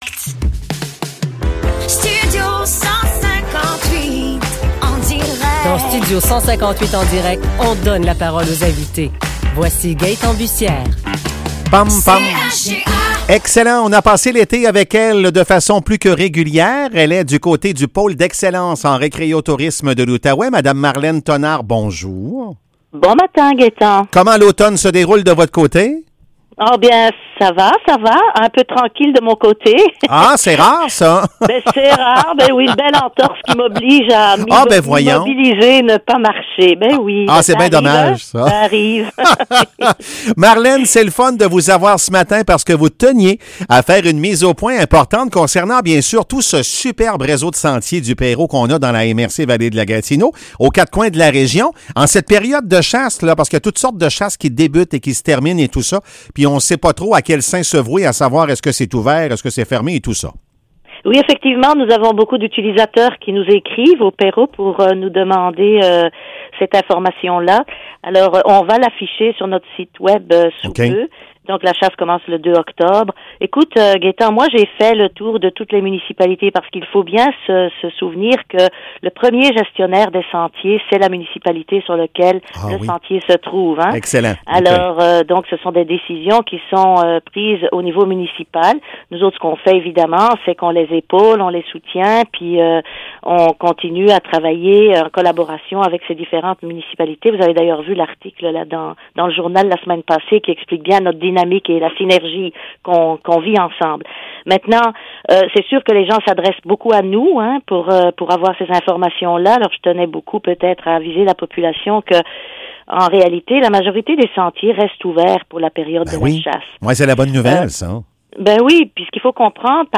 Épisode Chronique du PERO